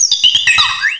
pokeemerald / sound / direct_sound_samples / cries / lilligant.aif